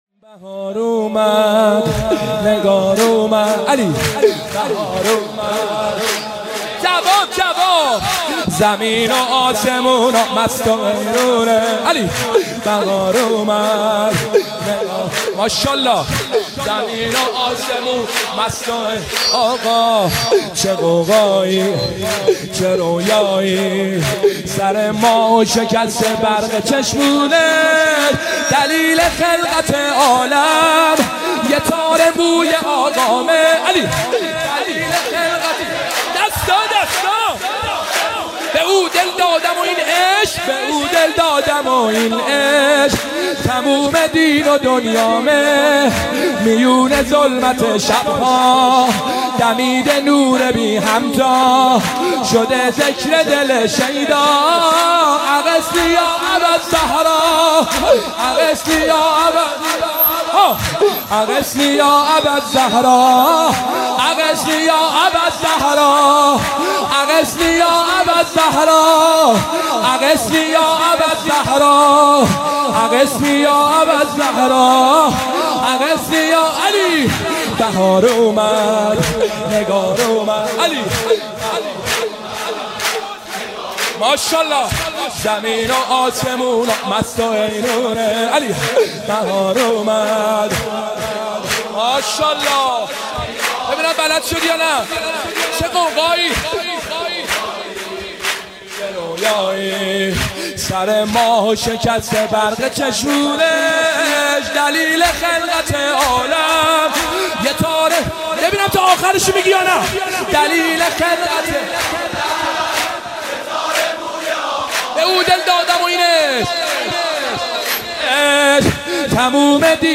مولودی
مولودی شاد پیامبر